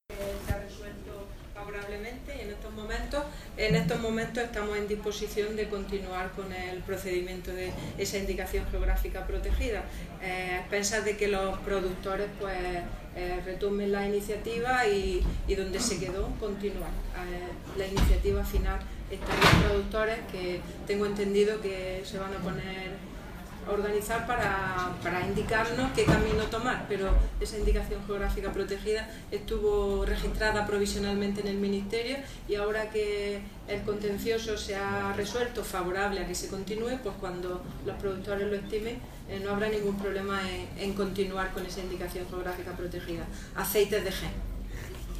Declaraciones de Carmen Ortiz sobre la Indicación Geográfica Protegida (IGP) Aceites de Jaén